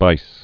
(bīs)